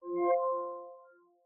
unlock.ogg